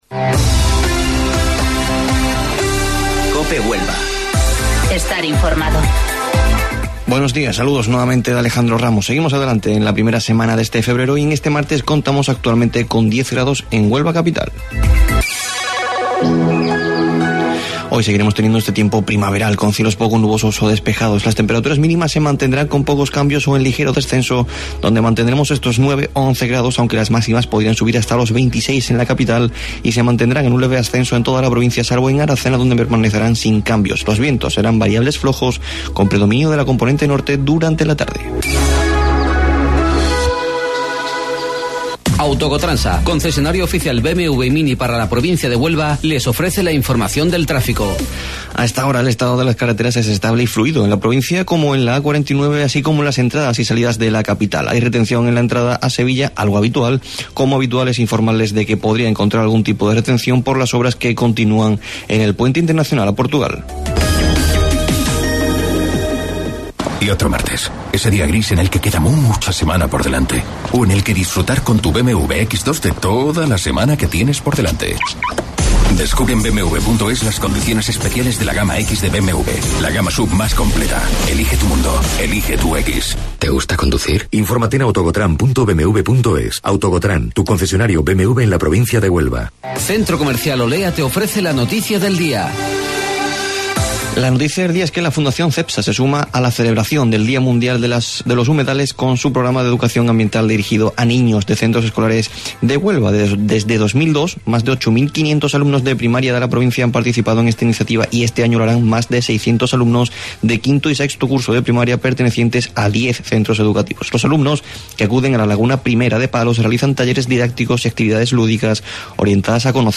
AUDIO: Informativo Local 08:25 del 4 de Febrero